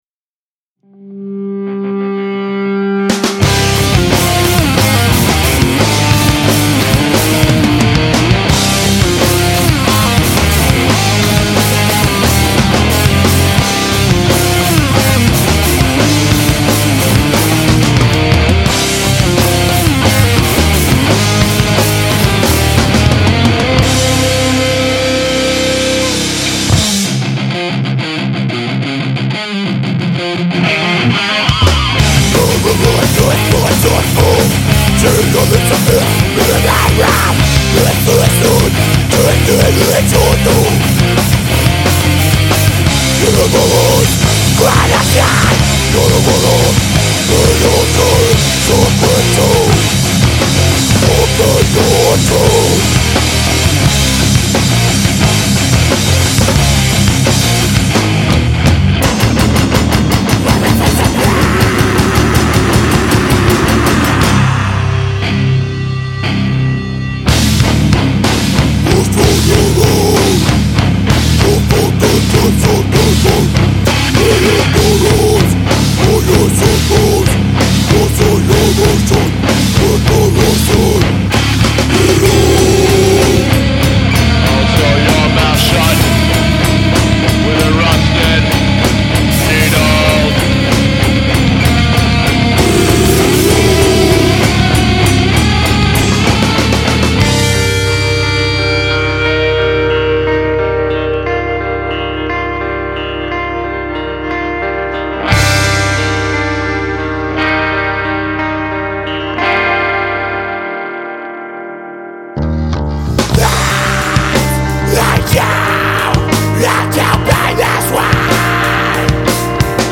Genre: Hard Core (Punk/Metal)